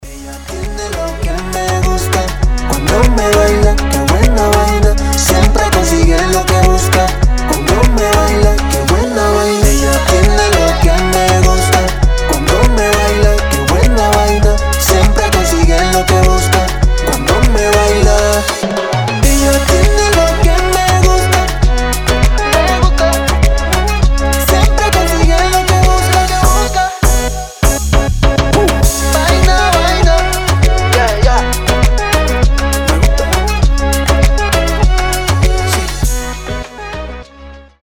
позитивные
мужской голос
ритмичные
заводные
Звучит свежо и по-летнему